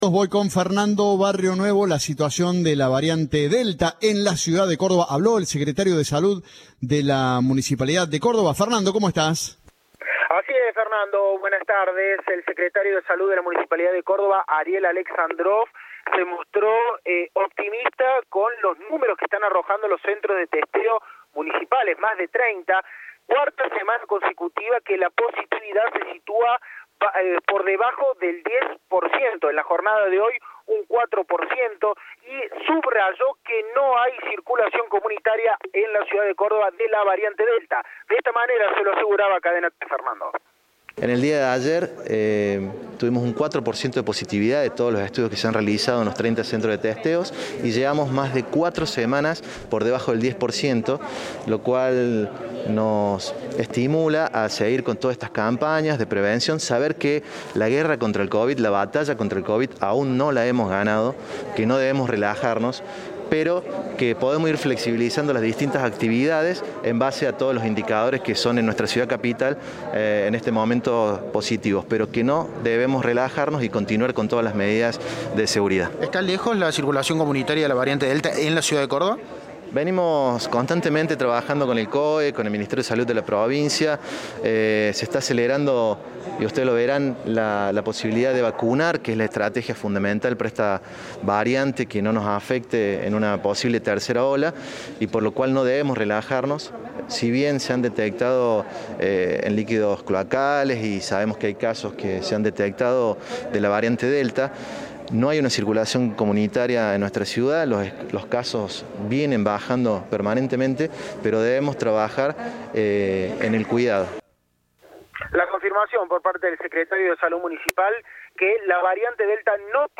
El secretario de Salud de la Municipalidad de Córdoba, Ariel Aleksandroff, negó en diálogo con Cadena 3 que haya transmisión comunitaria de la variante Delta en la ciudad, pese al brote que hubo en agosto.